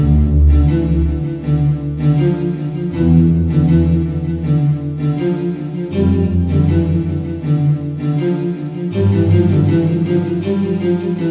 Category: Cinematic Ringtones